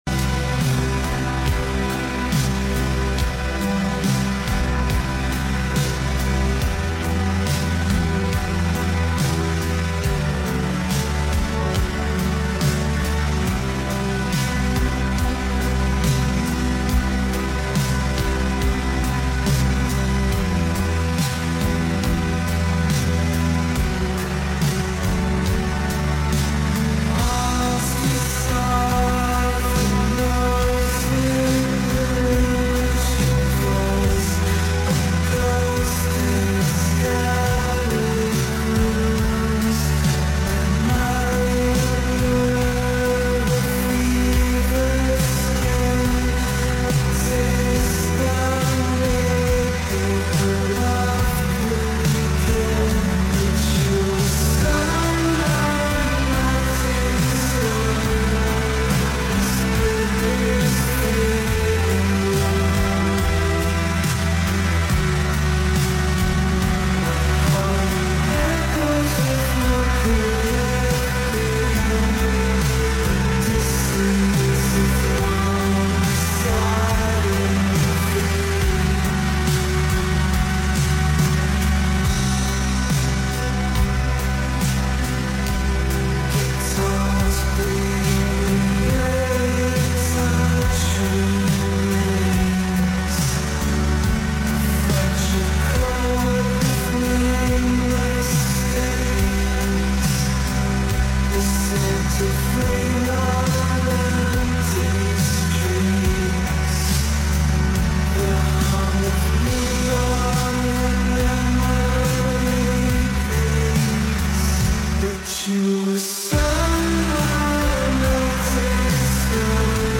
This is shoegaze/vaporwave/glitch. I wanted this to sound like lost media, give it a feeling of decay, as though someone recorded it off the radio 30 years ago, left it in a damp basement for awhile, and then uploaded it to the Internet.